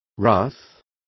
Complete with pronunciation of the translation of wraths.